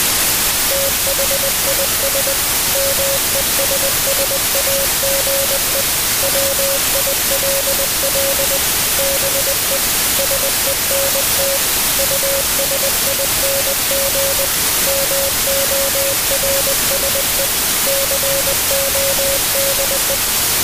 morse+noise.mp3